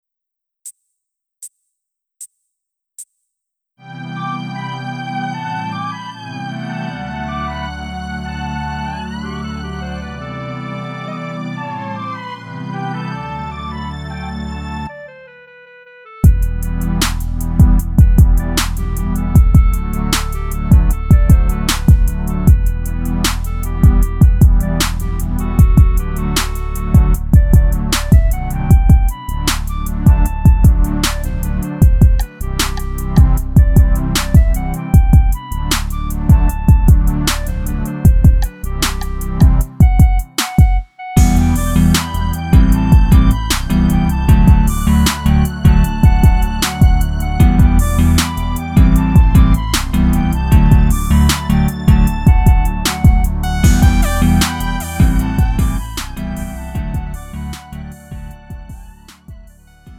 음정 -1키 2:44
장르 구분 Lite MR